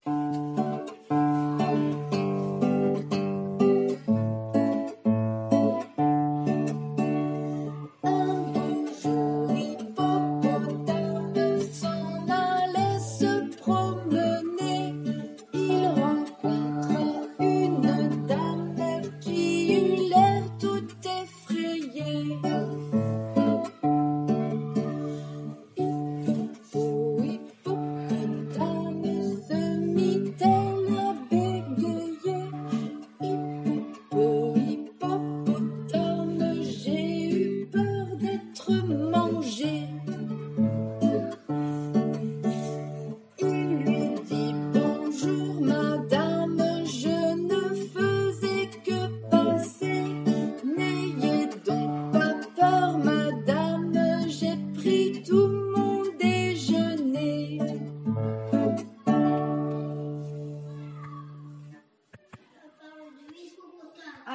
• Musique : voilà une petite chanson que vos enfants ont déjà apprise l’an dernier (sauf pour ceux qui n’étaient pas dans cette école).